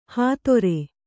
♪ hātore